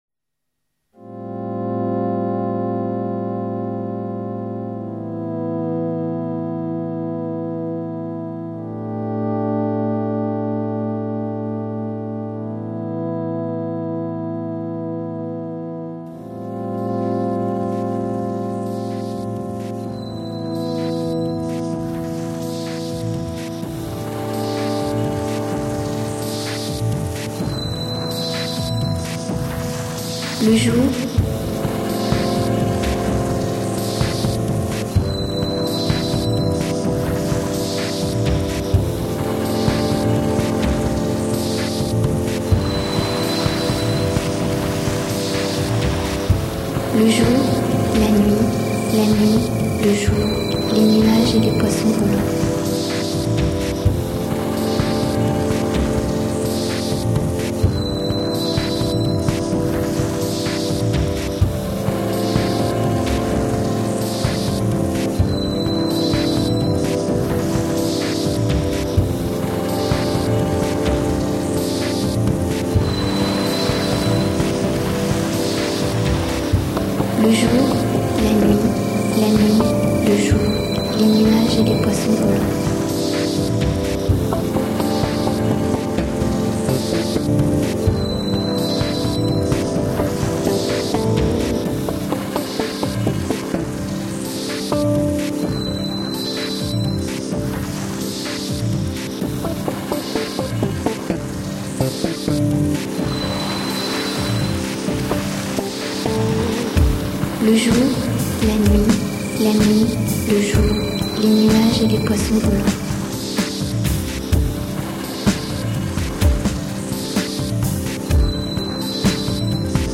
This little musical number started as an experiment in electronic music during which I tried to rework soundscapes and impressions of last year's animations for the Ars Electronica Festival.
Believe it or not, the music represents the dreamlike feeling I get when wandering about with widened, child-like eyes during Ars Electronica...